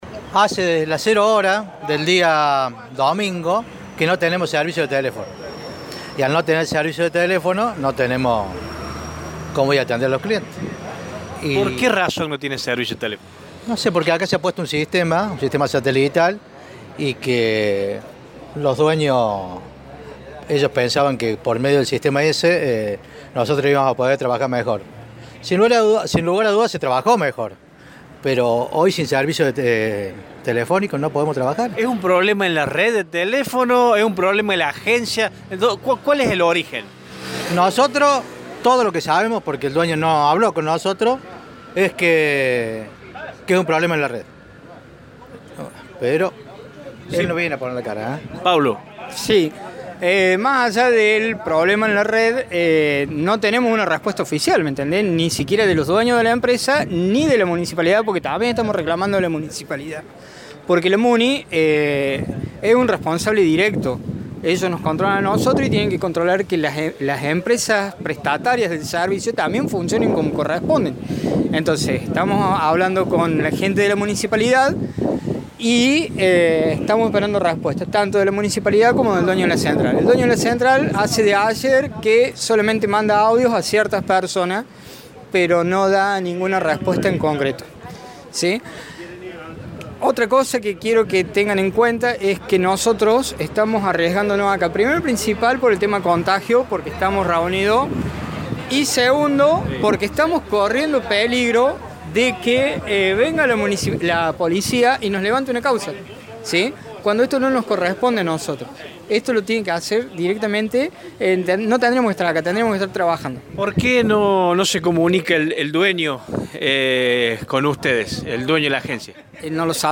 Esto decían dos de los trabajadores del volante que mostraban su malestar.